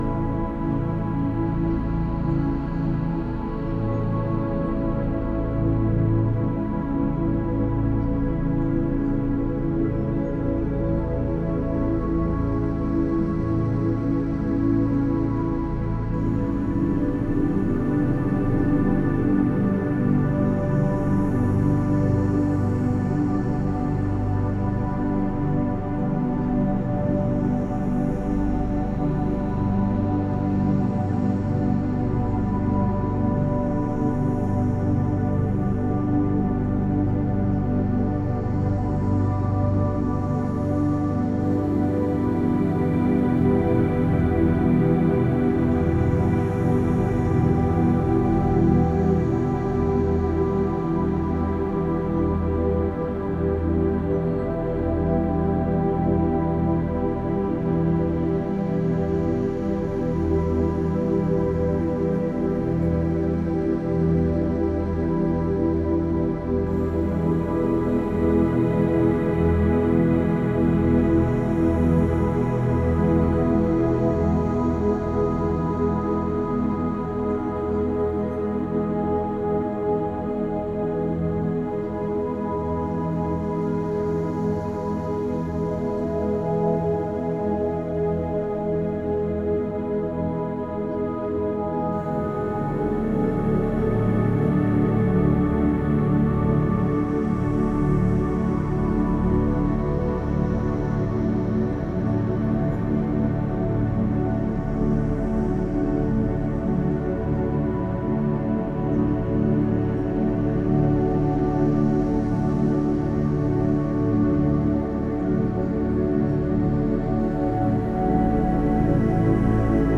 tropics.ogg